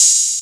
oh_metro.wav